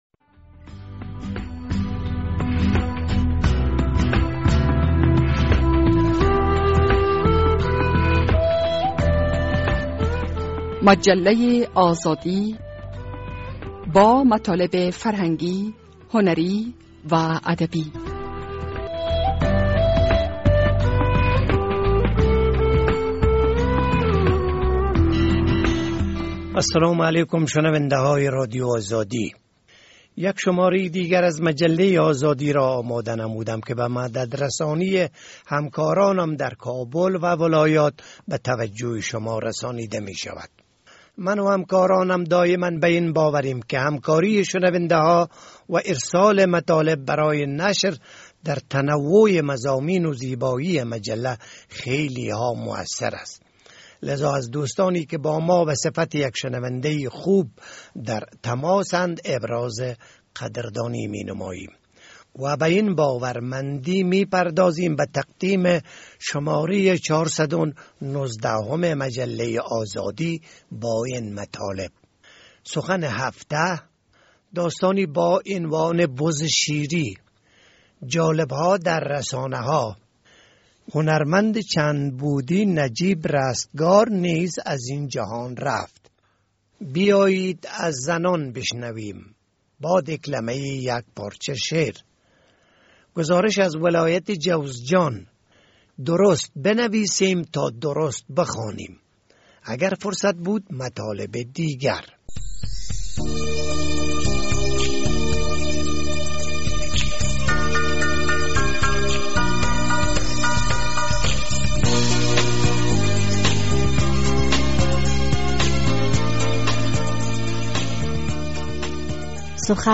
در این شماره مجله آزادی این مطالب جا یافته اند: سخن هفته، داستانی با عنوان (بزشیری) جالب‌ها در رسانه‌ها، بیآیید از زنان بشنویم با دیکلمۀ یک پارچه شعر، گزارش از ولایت جوزجان، درست بنویسیم تا درست بخوانیم، تهیه و نگارش